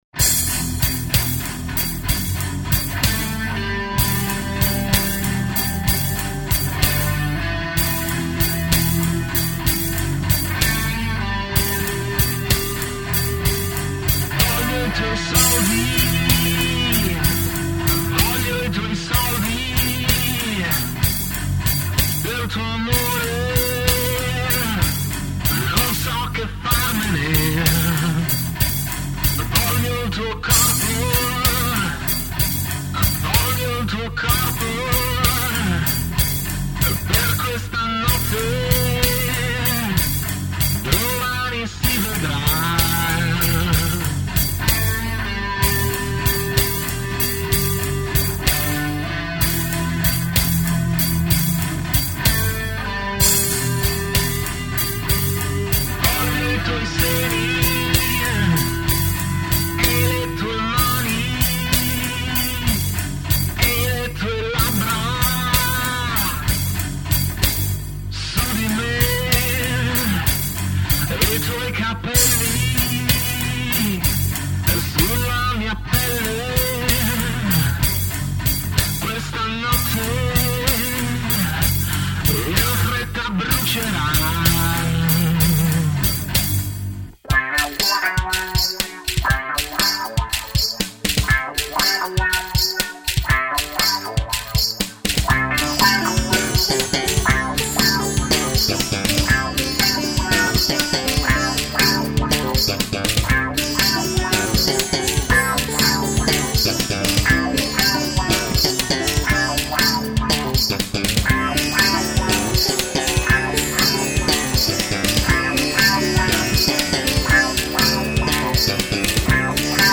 Voce e Programming
Basso e Programming
Chitarre e Programming